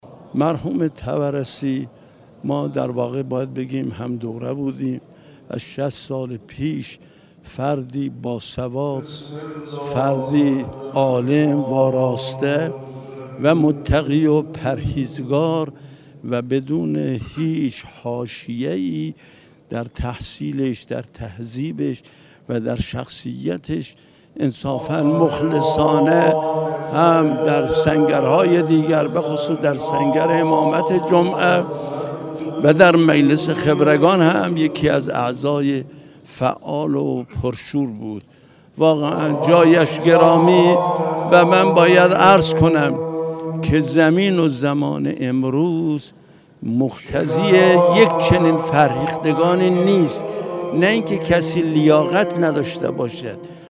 آیت الله سید هاشم بطحایی نماینده مجلس خبرگان رهبری در گفت وگو با خبرنگار سیاسی خبرگزاری رسا، با بیان اینکه مرحوم آیت الله طبرسی شخصیتی باسواد، عالم، وارسته، متقی و پرهیزکار بود، گفت: ایشان شخصیتی بی حاشیه بود و در همه امور خود مخلصانه عمل می کرد.